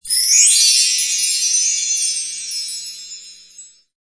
Bell Tree.mp3